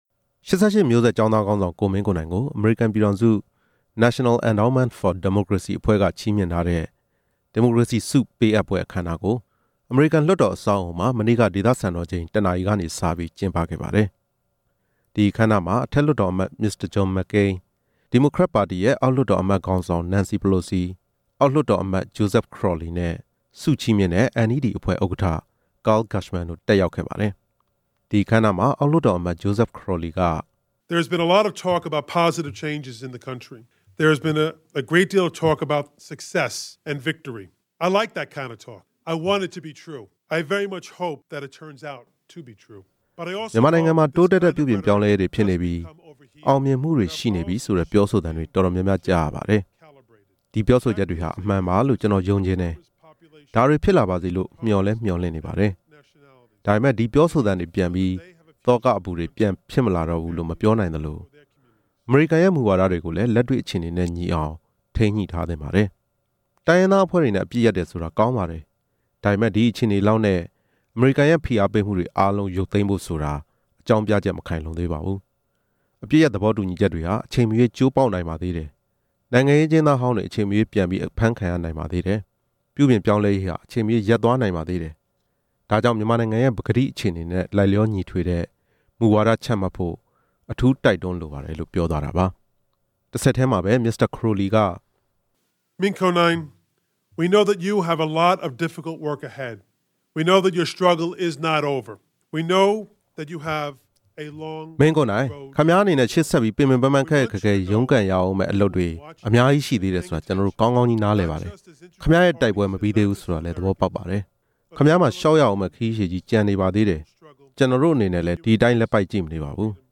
ကမ္ဘာတဝှမ်း ဒီမိုကရေစီ ထွန်းကားရေးအတွက် အားပေးကူညီနေတဲ့ အမေရိကန် ပြည်ထောင်စု National Endowment for Democracy (NED) အဖွဲ့က ၈၈ မျိုးဆက်ကျောင်းသားခေါင်းဆောင် ကိုမင်းကိုနိုင်ကို ချီးမြှင့်ထားတဲ့ ဒီမိုကရေစီဆုပေးအပ်ပွဲ အခမ်းအနားကို ဝါရှင်တန်ဒီစီ ဒေသစံတော်ချိန် အင်္ဂါနေ့ နေ့လည် ၁ နာရီကနေ ၃ နာရီထိ အမေရိကန်လွှတ်တော် ခန်းမတစ်ခုမှာကျင်းပခဲ့ပါတယ်။